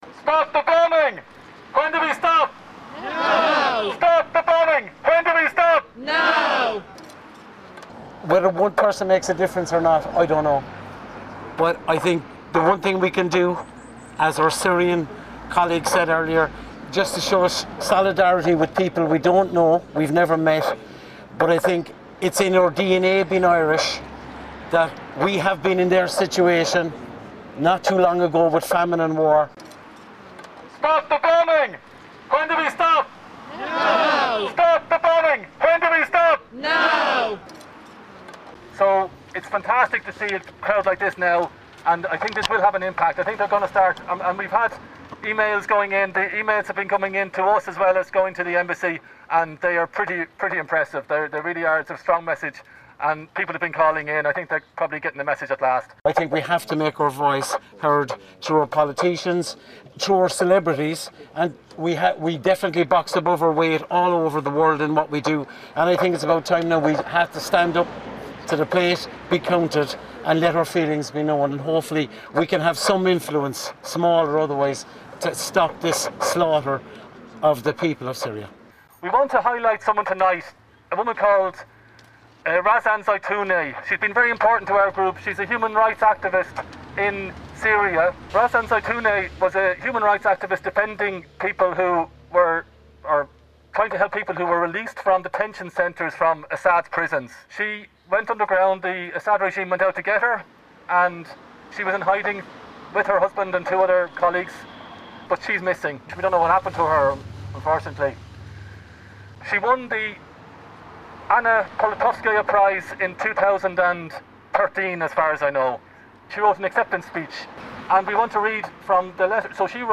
went along and recorded this report.